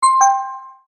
alert4.mp3